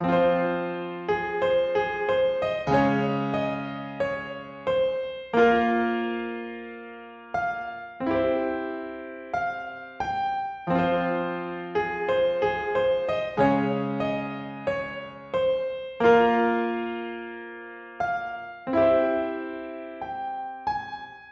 EPIC SAD KEYS 1适用于Trap Hip Hop和其他类型的音乐。
Tag: 90 bpm Hip Hop Loops Piano Loops 3.59 MB wav Key : Fm FL Studio